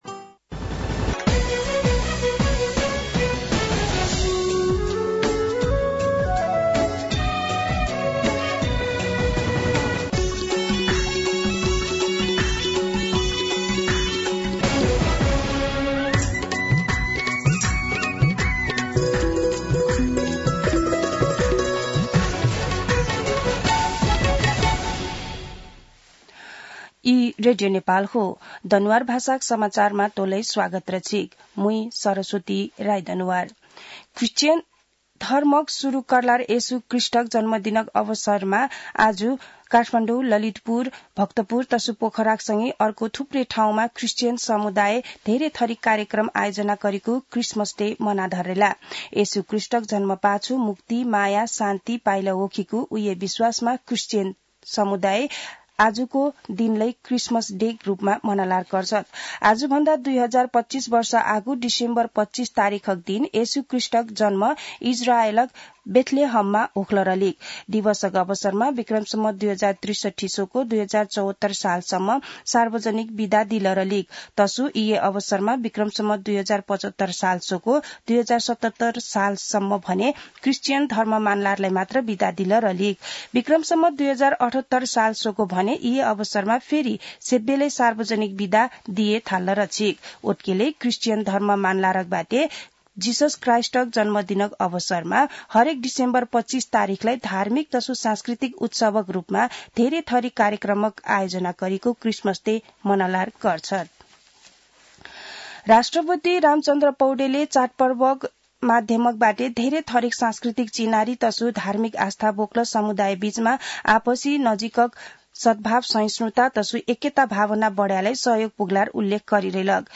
दनुवार भाषामा समाचार : ११ पुष , २०८१
Danuwar-News-10.mp3